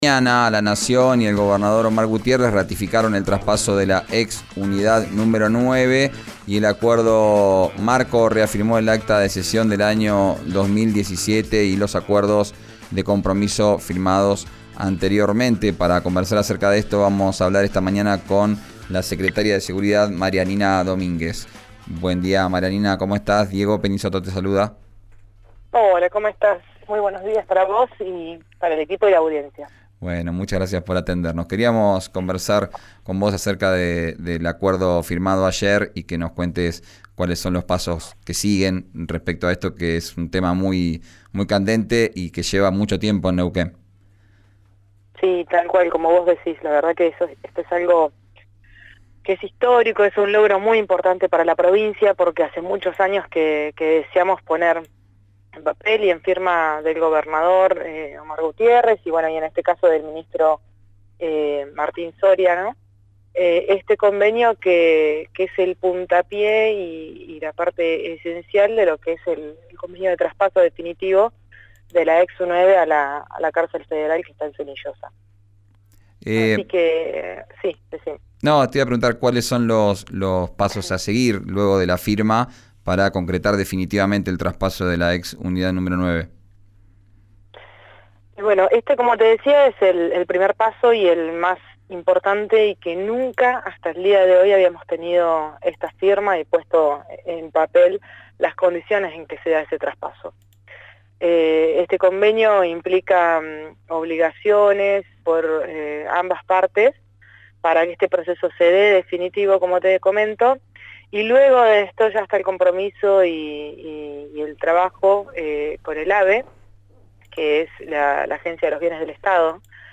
En diálogo con Arranquemos, el programa de RÍO NEGRO RADIO, la funcionaria dijo que en este momento la superpoblación en las cárceles provinciales supera las 170 personas.
Escuchá a la secretaria de Seguridad, Marianina Domínguez, en RÍO NEGRO RADIO: